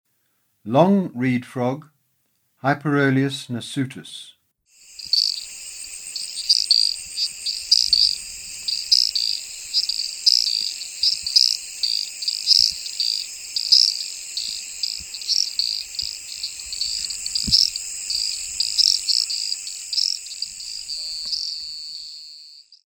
Especie: Hyperolius nasutus
Órden: Anura
Observaciones: Track narrado